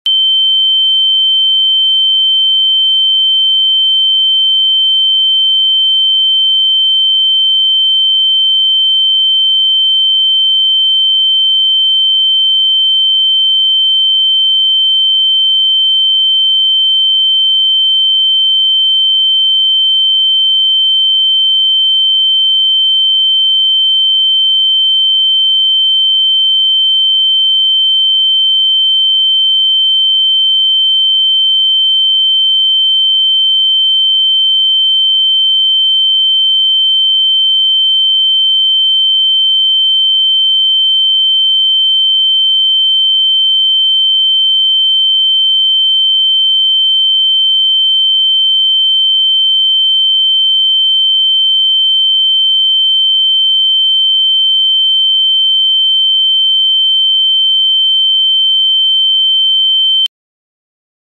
🌟 Improve Your Concentration with #3101Hz!